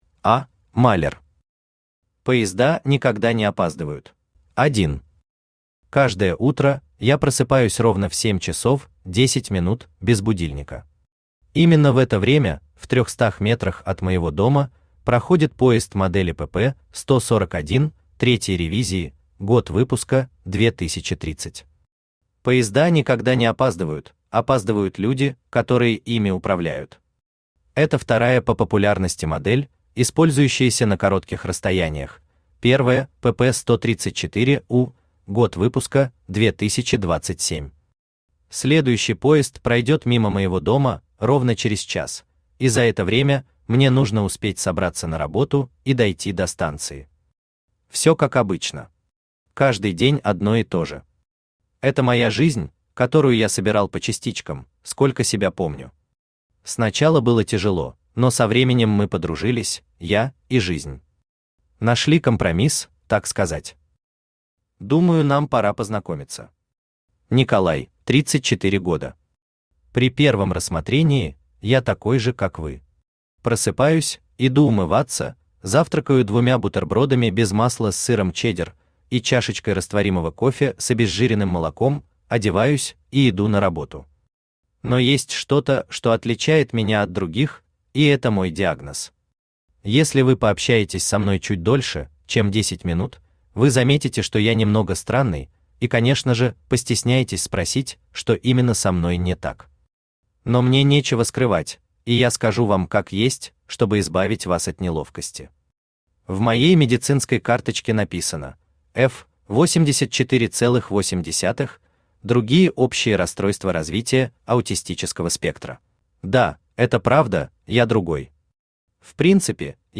Читает: Авточтец ЛитРес
Аудиокнига «Поезда никогда не опаздывают». Автор - А. Малер.